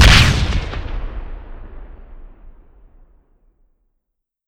Grenade Explosion.wav